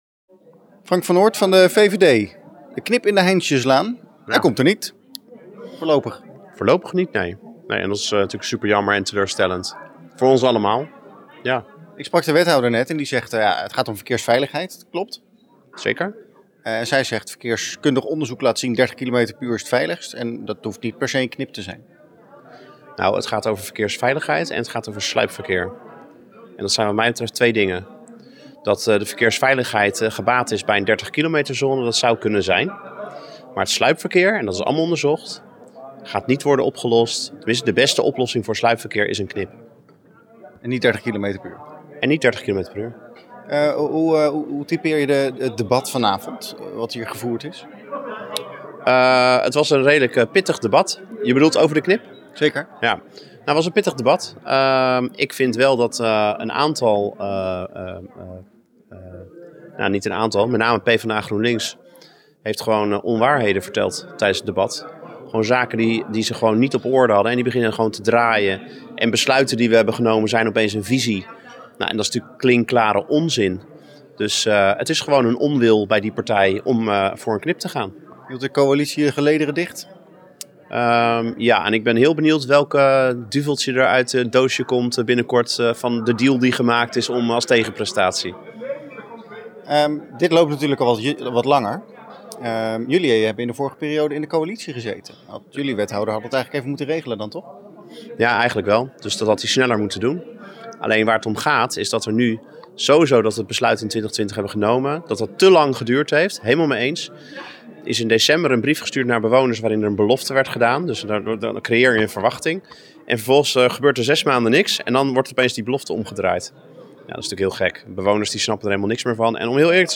AUDIO: VVD Leiderdorp fractievoorzitter Frank van Noort over de knip.